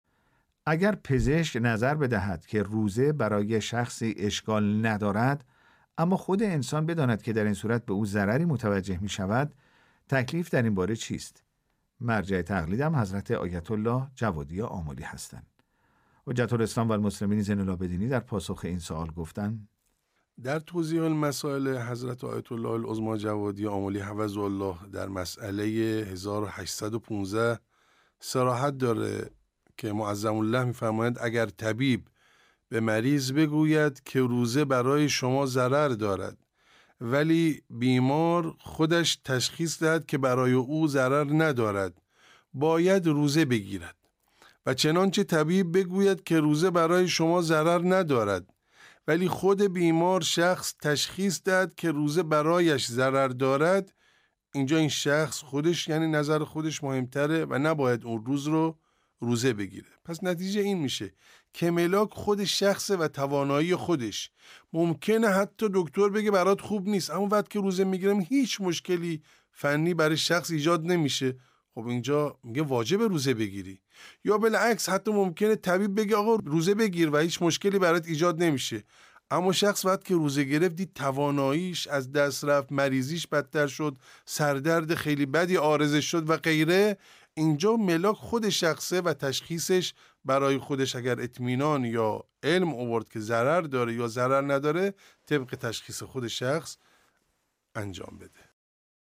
پاسخ نماینده دفتر آیت الله العظمی جوادی آملی